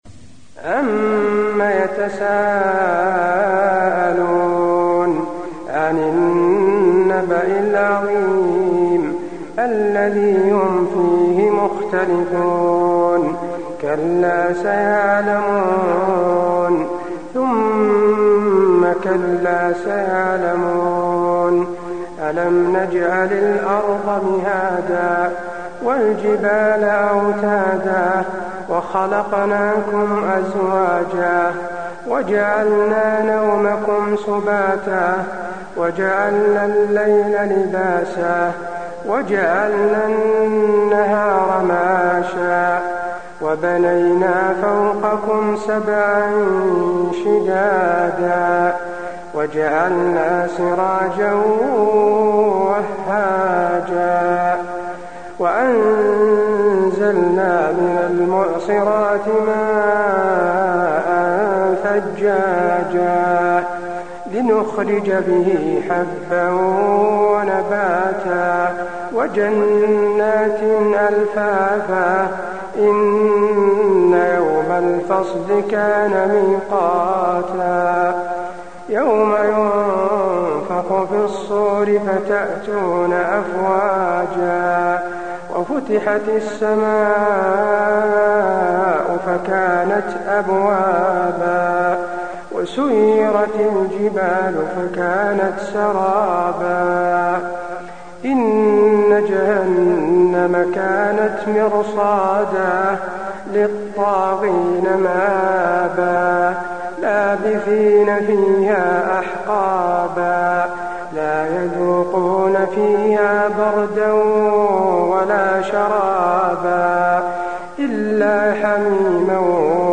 المكان: المسجد النبوي النبأ